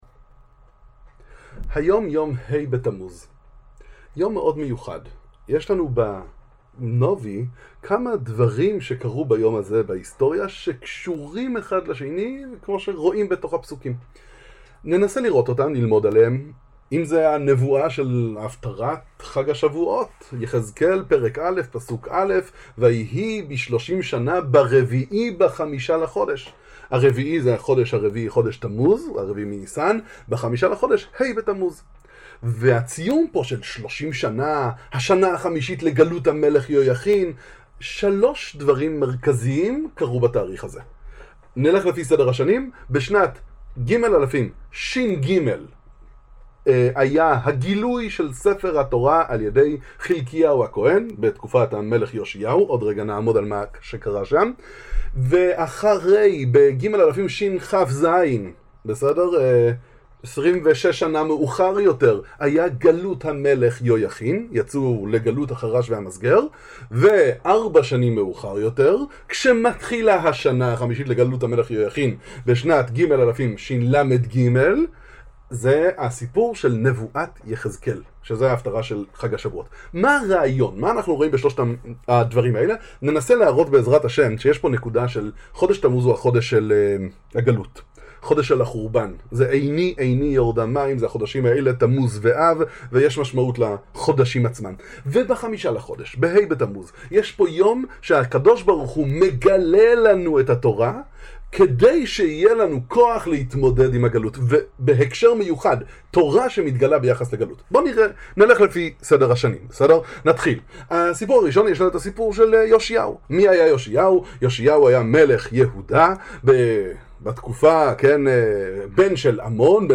דברי תורה קצרים חיזוק לקראת הגאולה